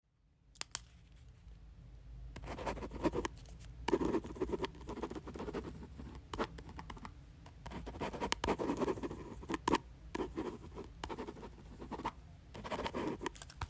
Play and download "elevator door and ding" sound effect free. elevator in chicago building dinging, opening, and closing Share on Discord, TikTok & WhatsApp.
elevator door and ding